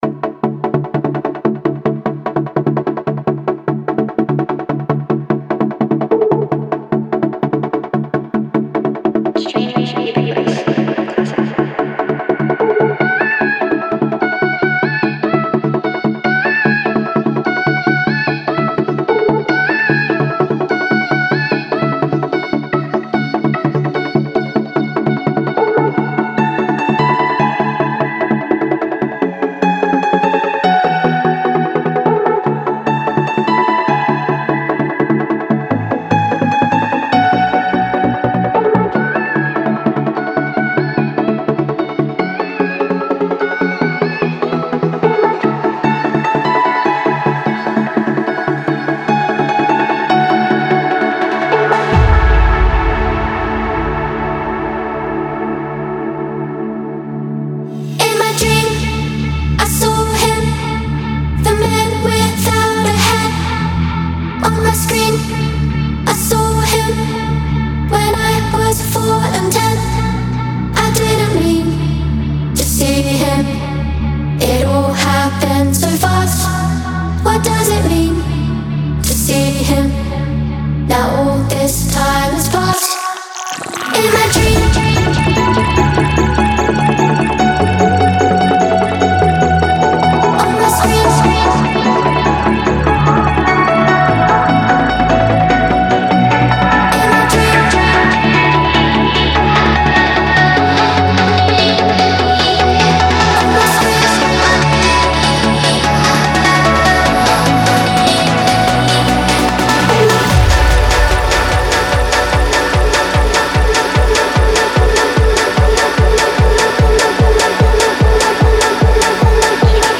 BPM148-148
Audio QualityPerfect (High Quality)
Full Length Song (not arcade length cut)